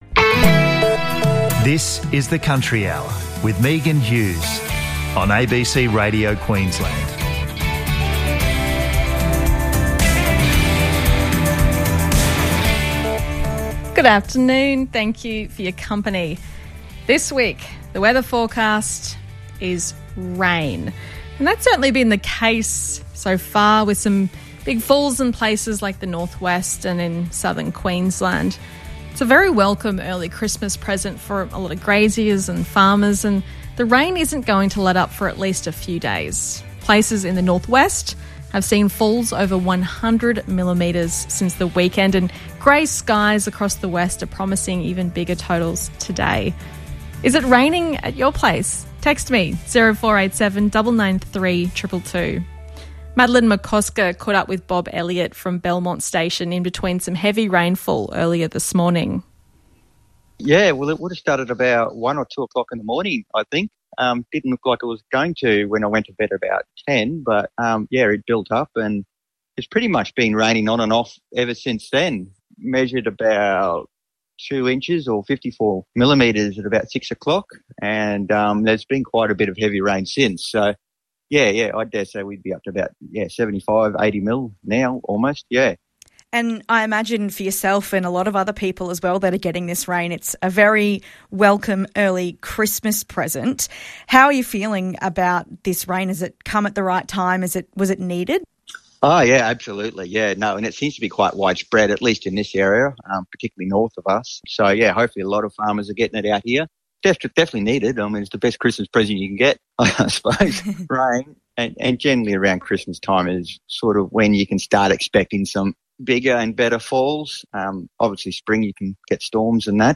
In this in-depth conversation